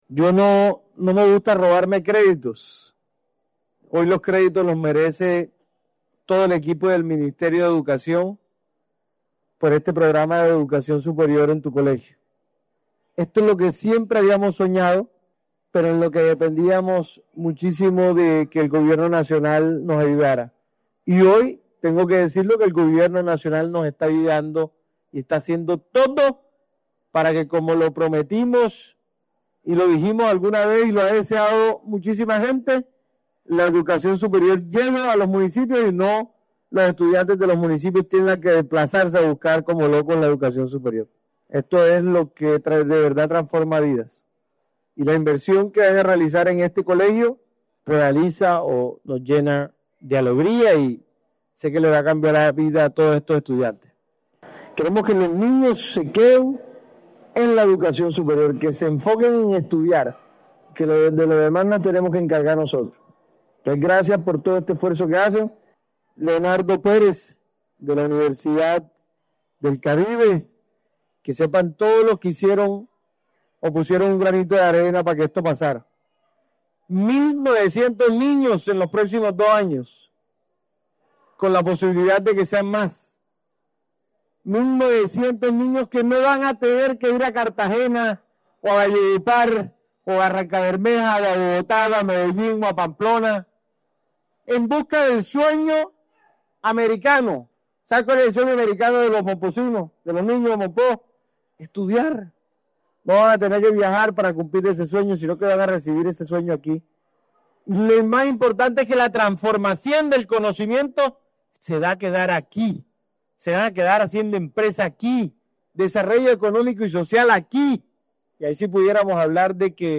YAMIL-ARANA-GOBERNADOR-DE-BOLIVAR.mp3